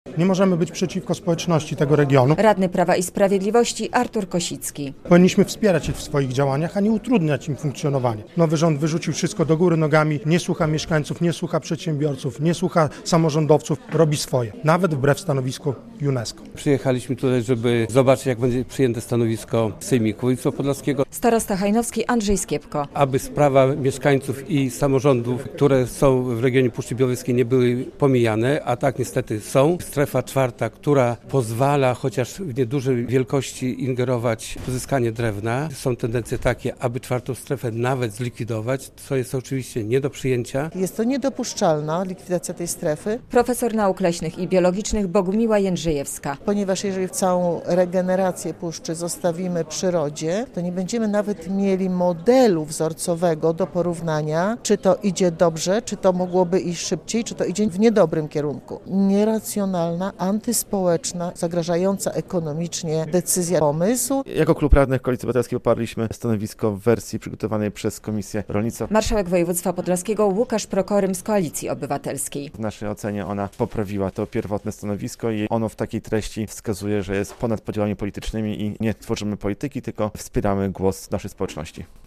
Sejmik przeciwko zmniejszeniu terenów gospodarczych Puszczy Białowieskiej - relacja